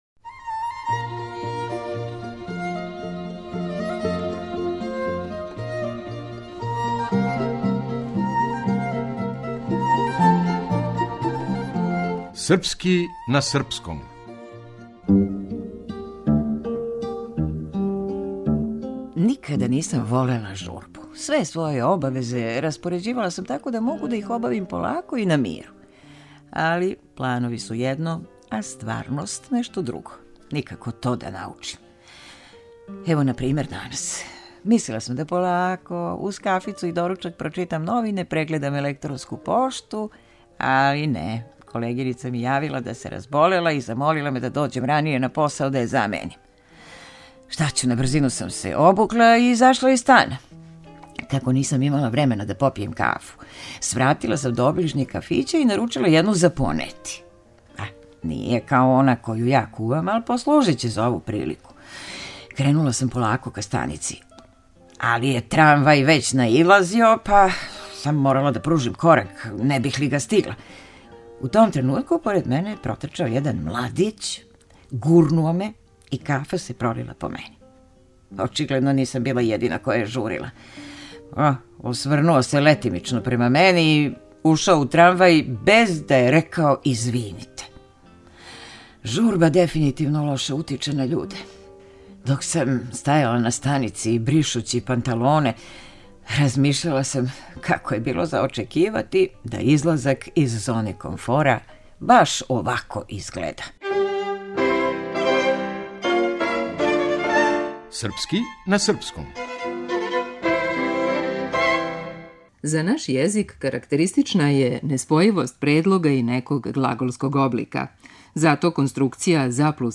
Глумица